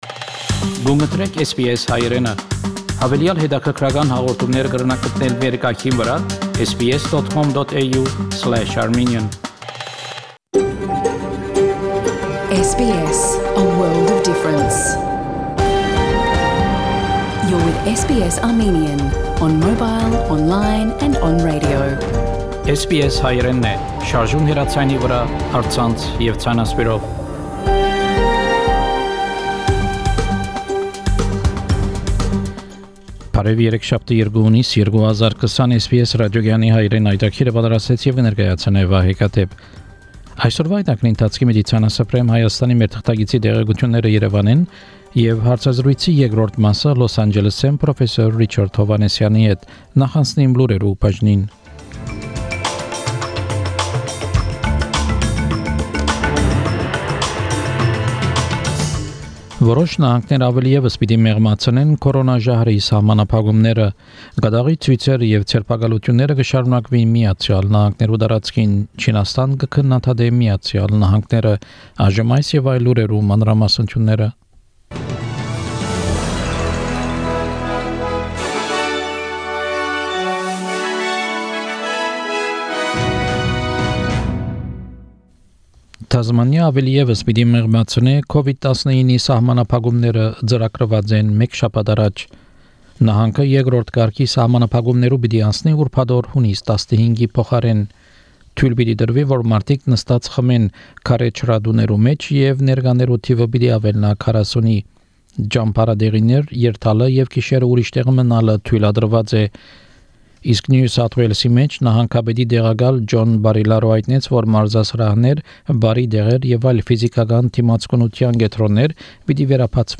SBS Armenian news bulletin – 2 June 2020
SBS Armenian news bulletin from 2 June 2020 program.